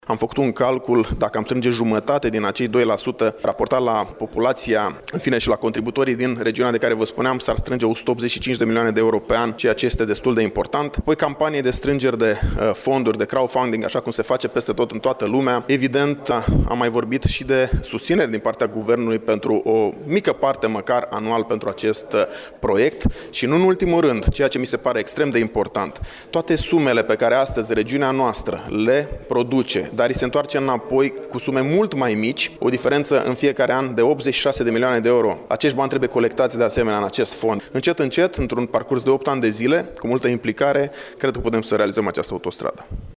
Astăzi, într-o conferinţă de presă, Marius Bodea, membru al comisiei pentru Transporturi din Camera Deputaţilor, a precizat că din această asociaţie ar putea face parte reprezentaţi ai consiliilor judeţene din Mureş, Harghita Neamţ şi Iaşi, precum şi primarii localităţilor de pe axa autostrăzii.